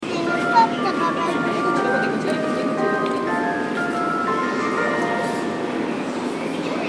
みなとみらい駅　Minatomirai Station ◆スピーカー：National天井型
2番線発車メロディー